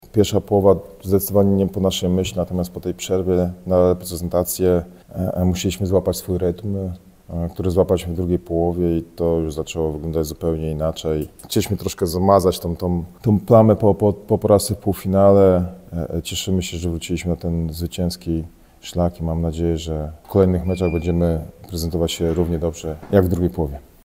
który tak podsumował ją na konferencji prasowej.